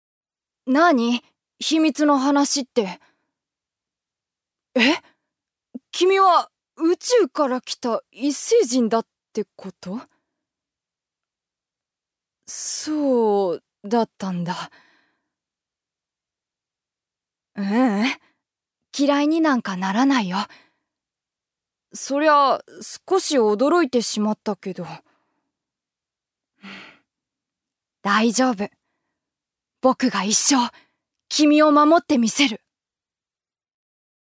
預かり：女性
セリフ４